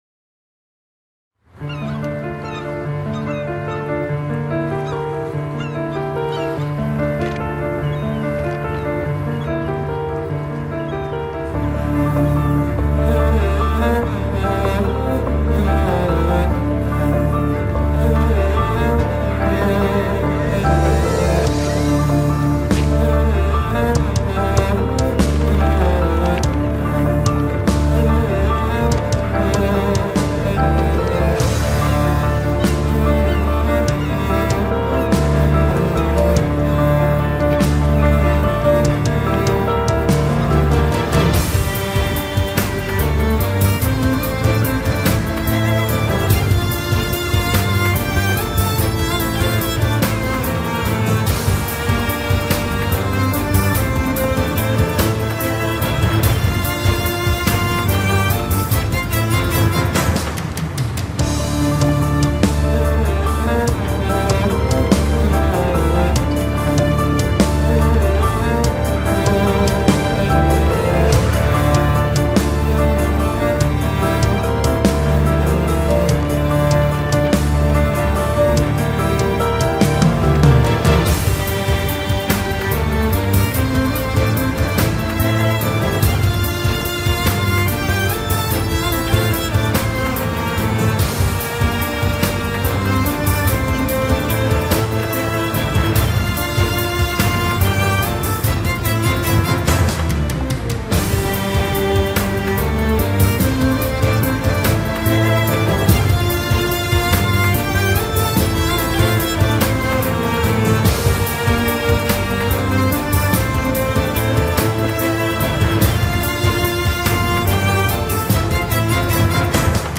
tema dizi müziği, duygusal hüzünlü üzgün fon müziği.